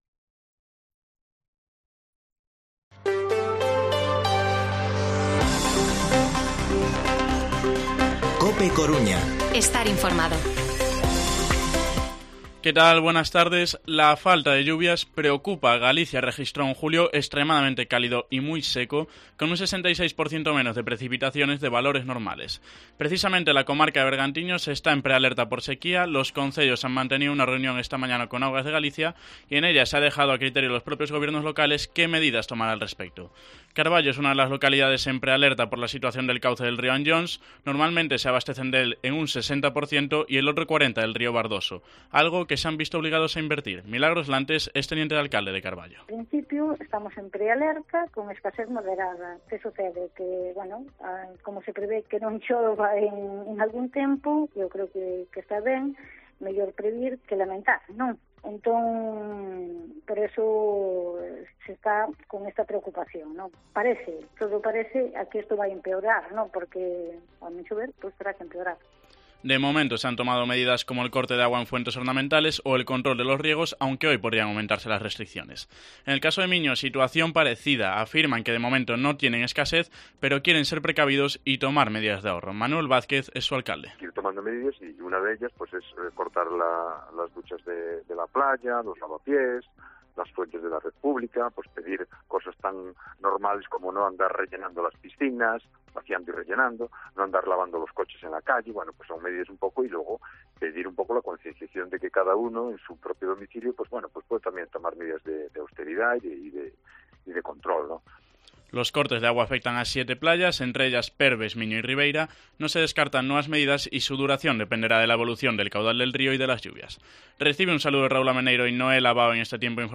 Informativo Mediodía COPE Coruña lunes, 8 de agosto de 2022 14:20-14:30